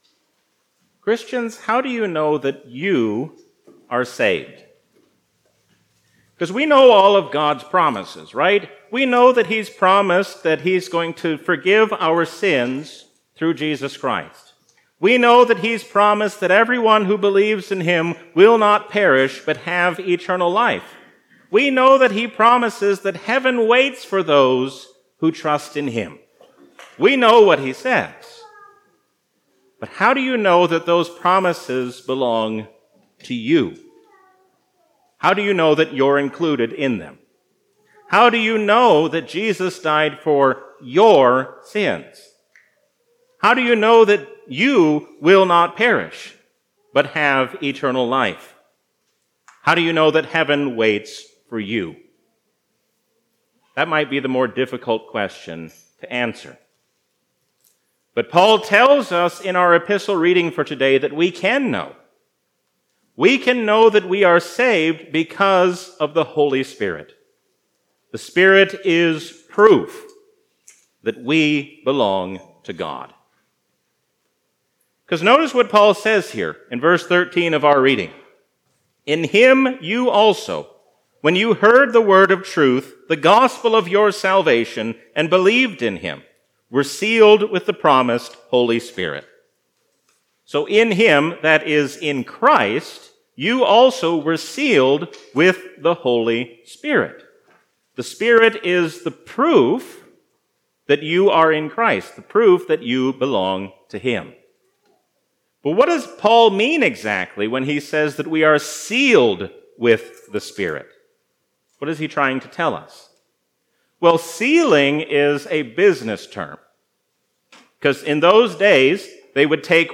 A sermon from the season "Easter 2023." Knowing who the Holy Spirit is changes how we live as Christians.